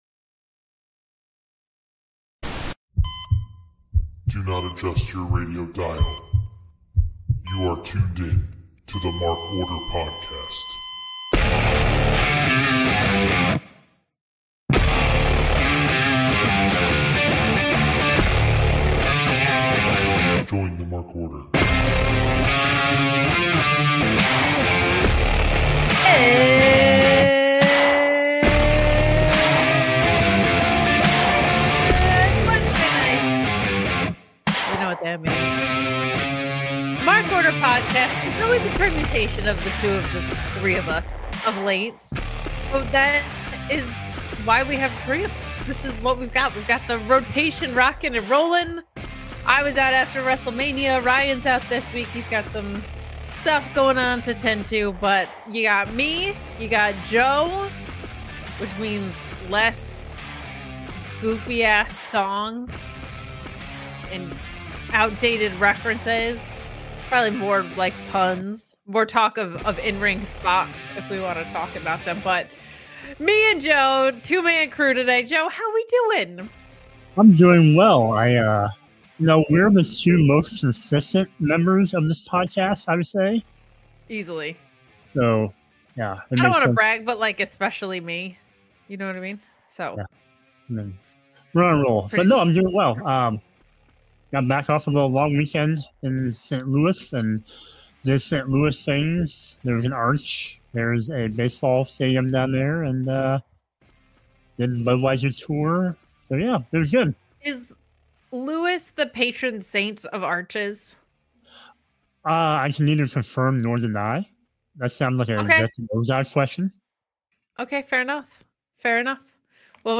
Hear these two lovely people talk about another great Dynamite.